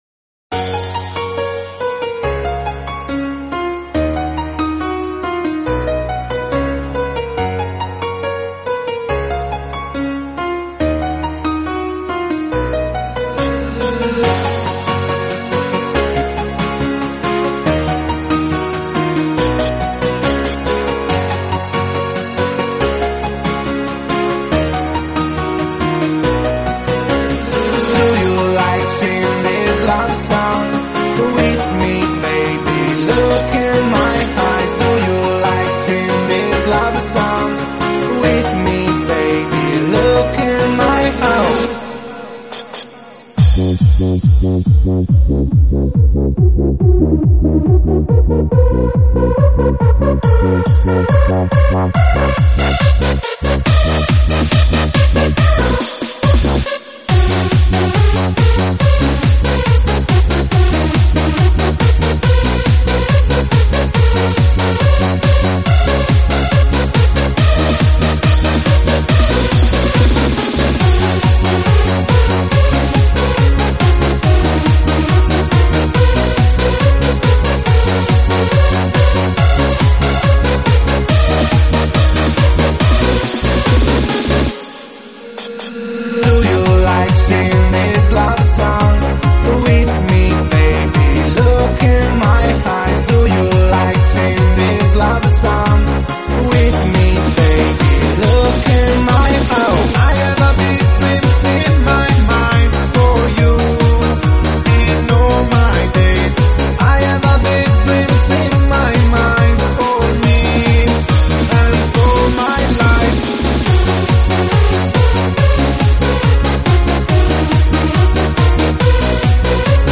经典 抖音 伤感情歌 暖场